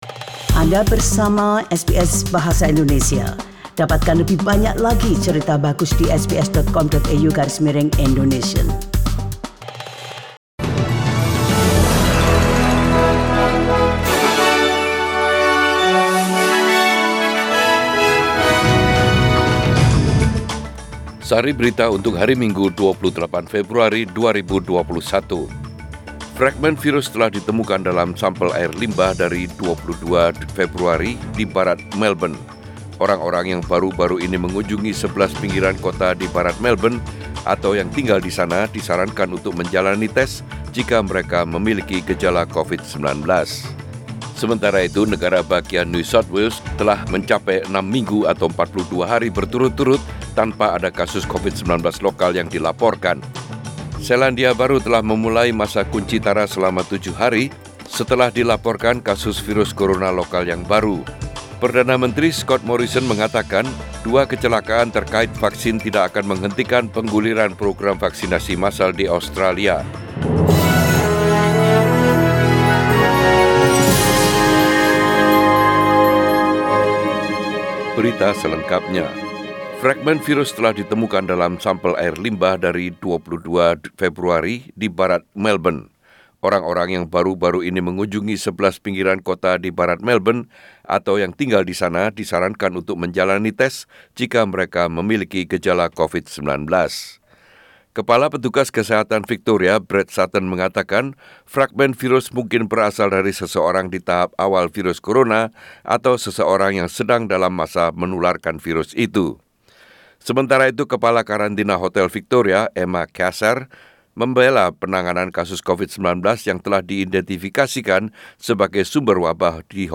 SBS Radio News in Bahasa Indonesia - 28 Feb 2021